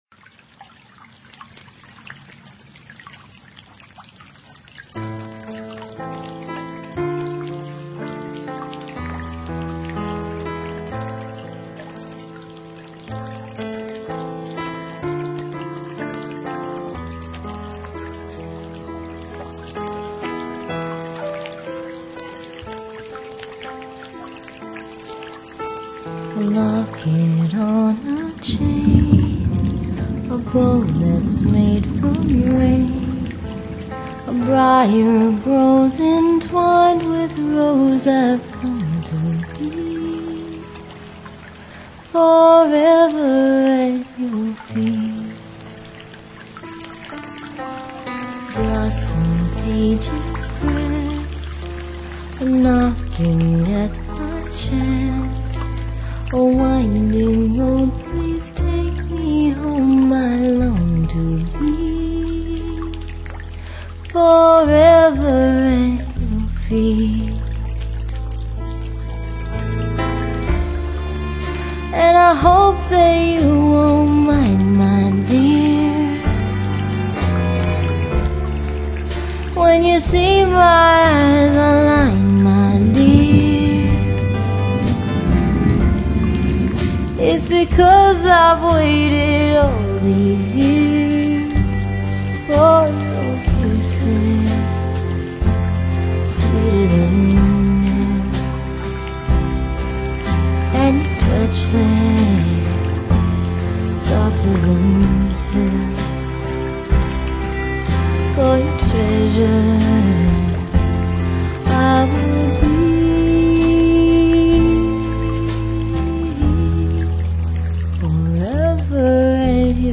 这是一位在加拿大长大的美国女子。